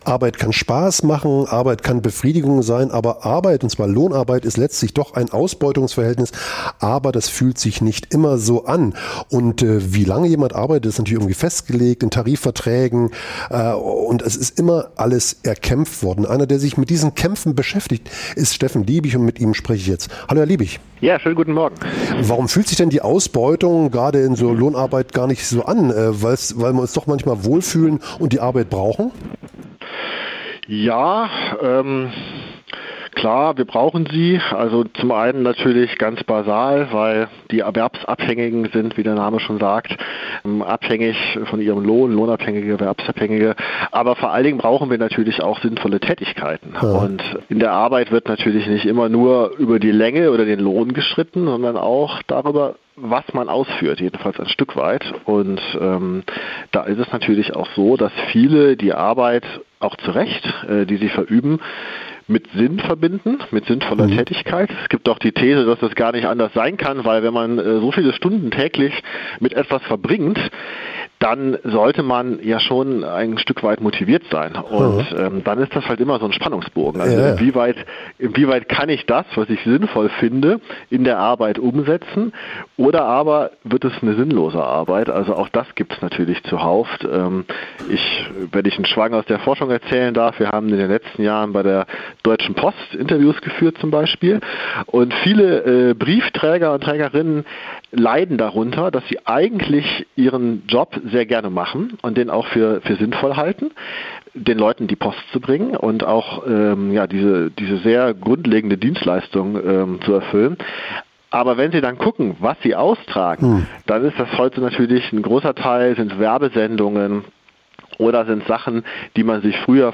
Das Gespr�ch